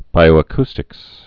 (bīō-ə-kstĭks)